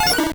Cri de Grodoudou dans Pokémon Or et Argent.